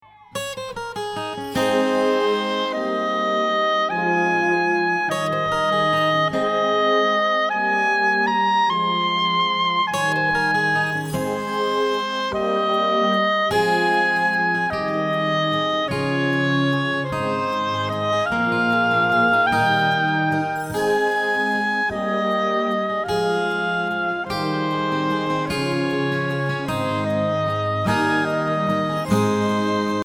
Listen to a sample of the instrumenal track.